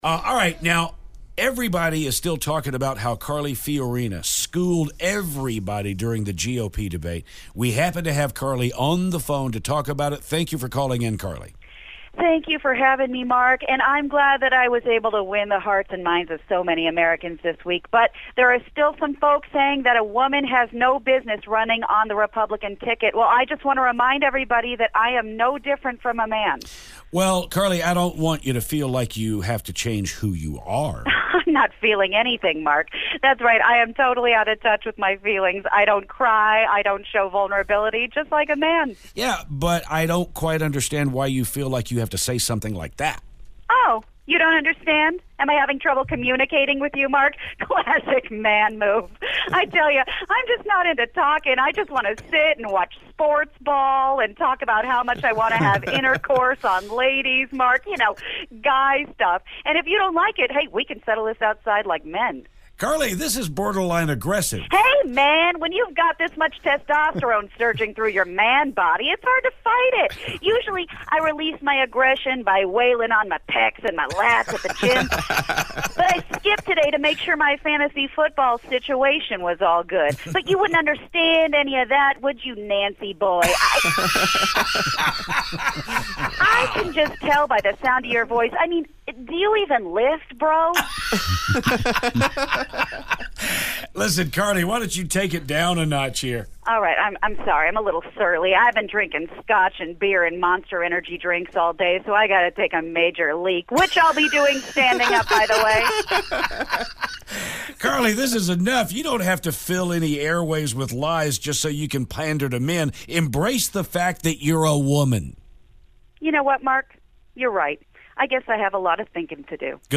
Carly Fiorina Phoner
Presidential candidate Carly Fiorina calls to talk about last weeks debate.